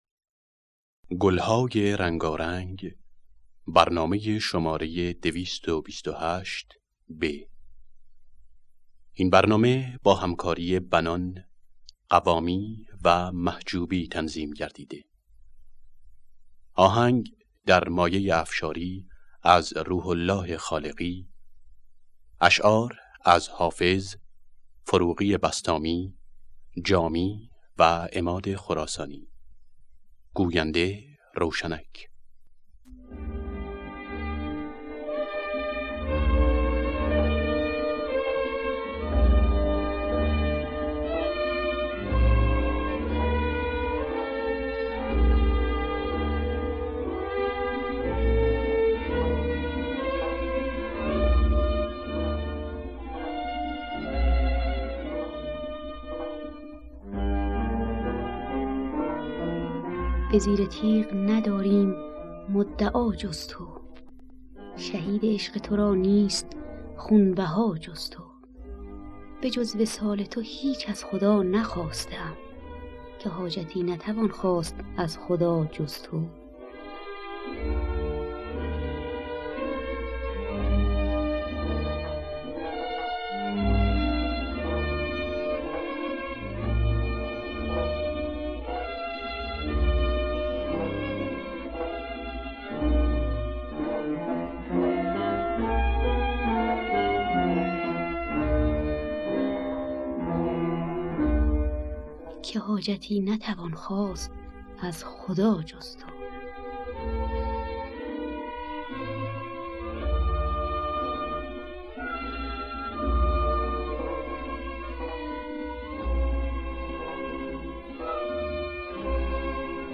گلهای رنگارنگ ۲۲۸ب - افشاری خوانندگان: بنان حسین قوامی نوازندگان: مرتضی محجوبی